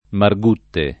Margutte [ mar g2 tte ]